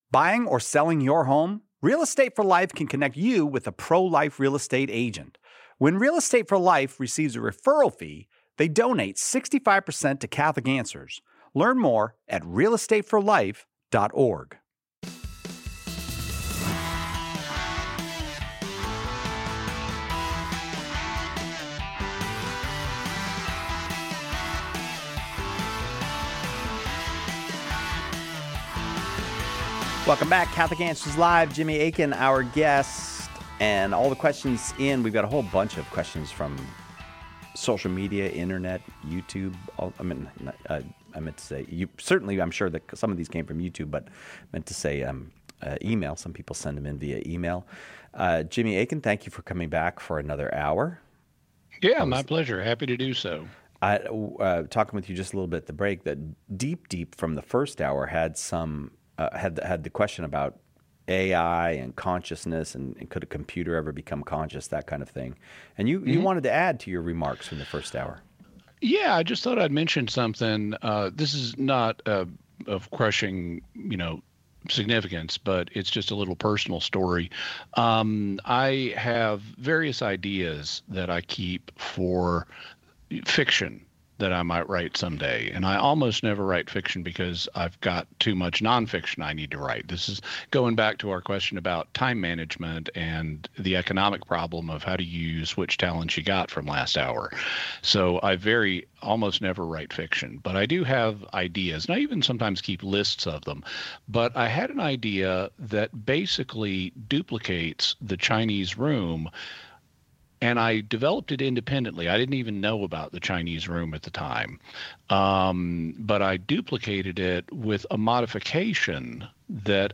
In this episode of Catholic Answers Live , Catholic Answers apologists address this common question before diving into a wide range of topics. They explore whether charging interest is still considered usury, how just war theory applies to rebellions, and how historical cases like the Knights Templar fit with Church law.